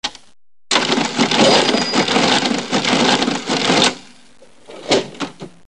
Registratore di cassa
Suono di registratore di cassa meccanico d'epoca con rumore manovella e campanello apertura cassa.
Effetto sonoro - Registratore di cassa